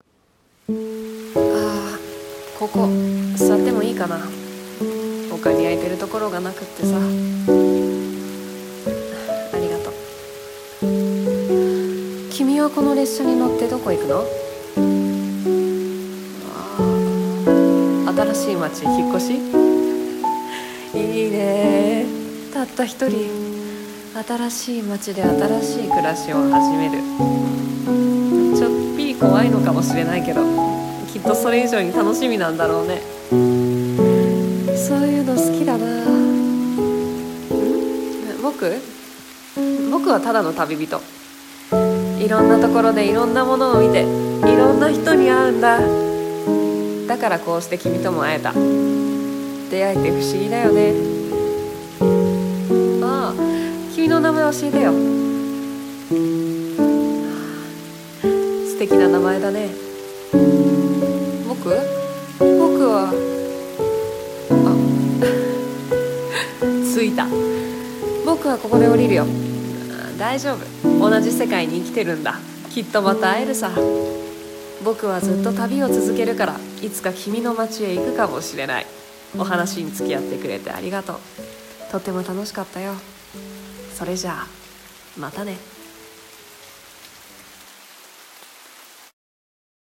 声劇「みしらぬネコ」